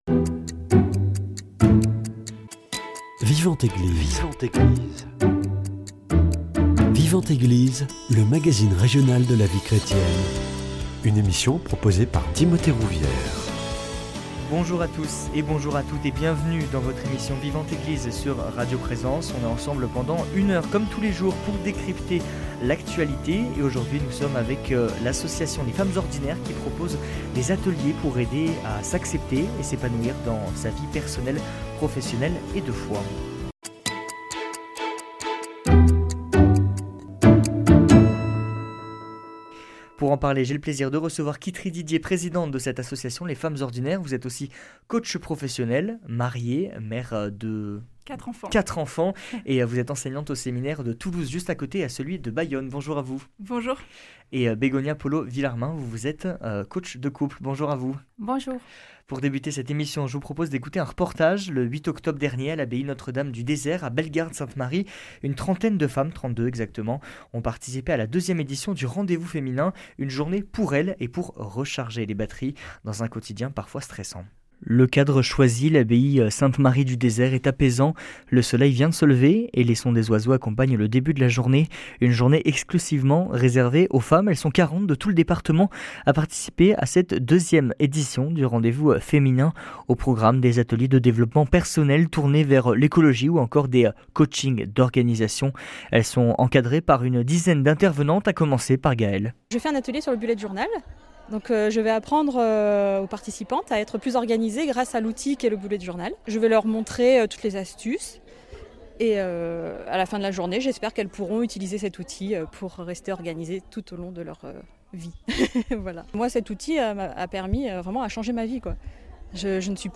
Une femme très présente pour les autres comme si cela faisait partie de sa nature. Comment la femme peut-elle penser à elle pour rayonner davantage ? Réponse avec nos invitées, deux femmes engagées.